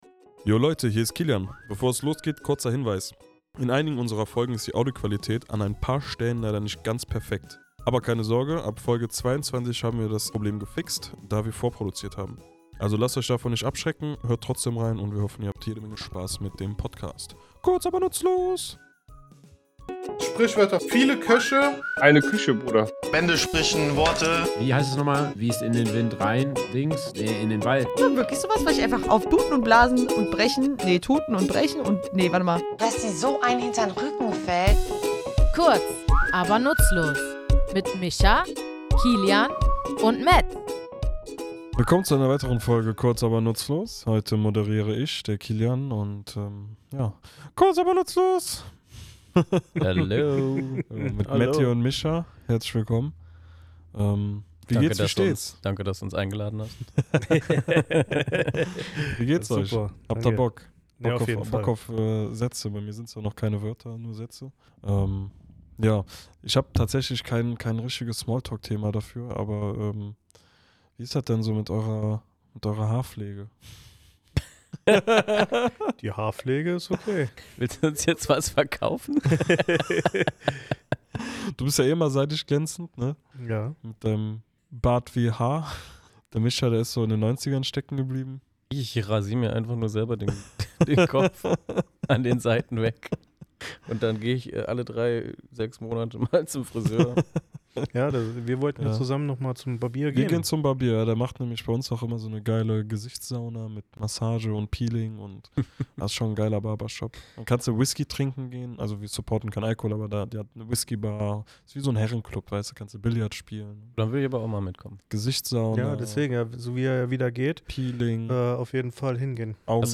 Wir, drei tätowierende Sprachfans, klären in unserem Tattoostudio die Herkunft und Bedeutung dieses Ausdrucks.